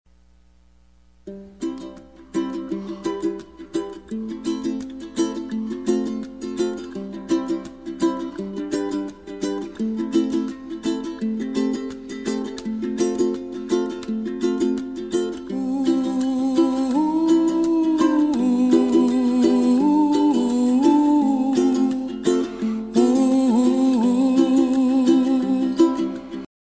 гитара
гавайская гитара